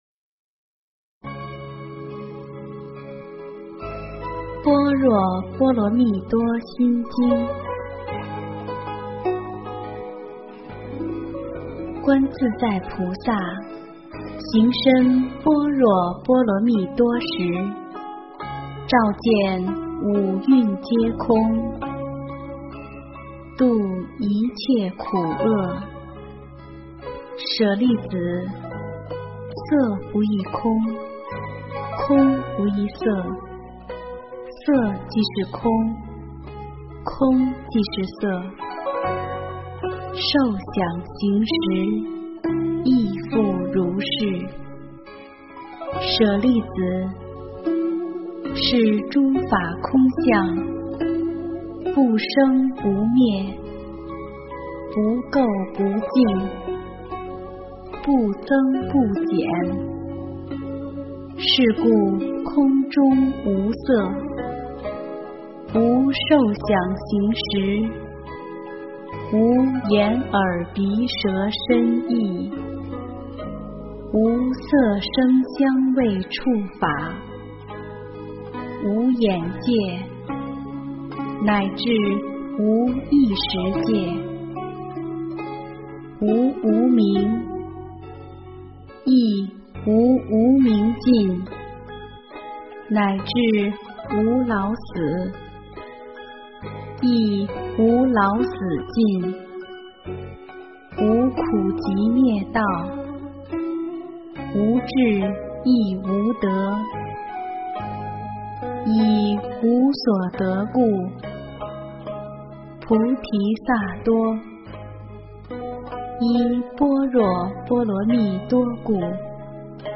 心经（念诵）
心经（念诵） 诵经 心经（念诵）--未知 点我： 标签: 佛音 诵经 佛教音乐 返回列表 上一篇： 大势至菩萨念佛圆通章 下一篇： 大势至菩萨念佛圆通章（念诵） 相关文章 九叩观音--华夏禅音乐团 九叩观音--华夏禅音乐团...